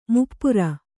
♪ muppura